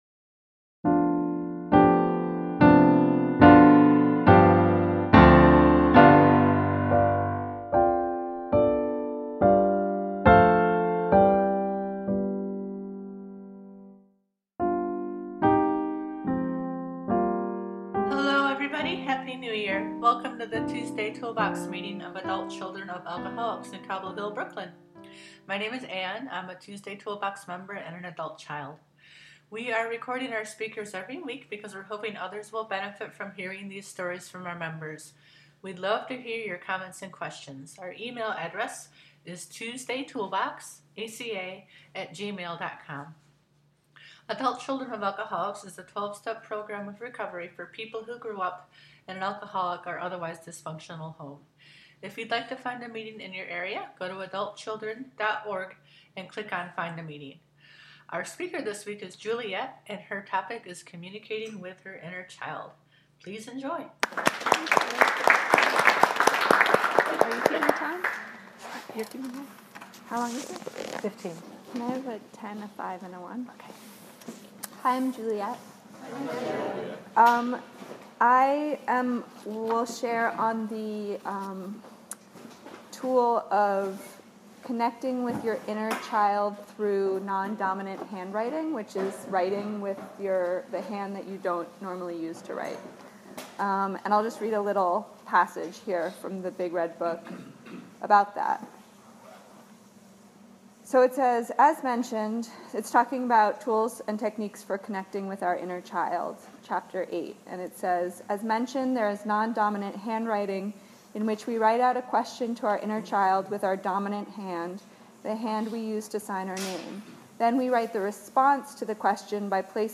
ACA Speaker Audios